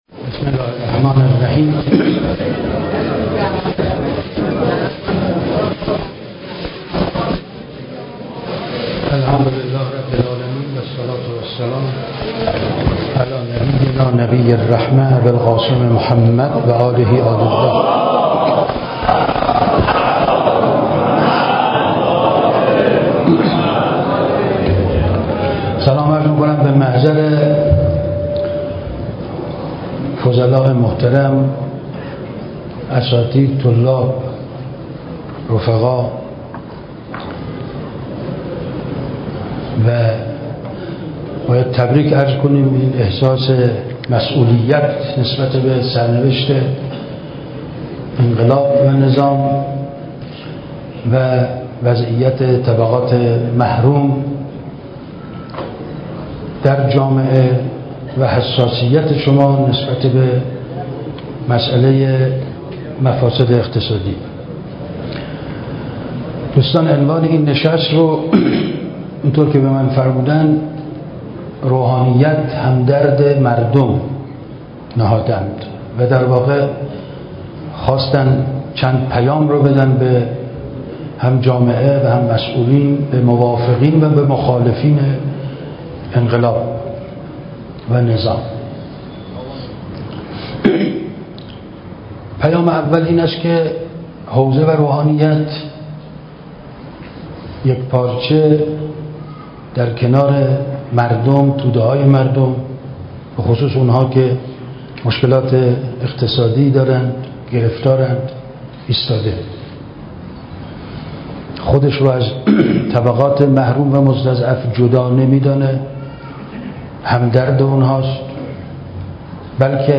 ۲۵ مرداد ۱۳۹۷ سخنرانی استاد رحیم پور ازغدی- فیضیه.mp3